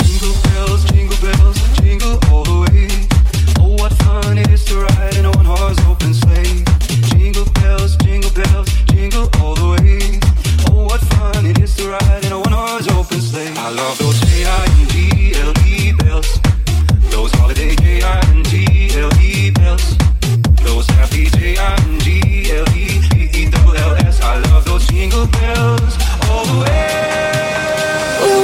Genere: edm,deep,bounce,house,slap,cover,remix hit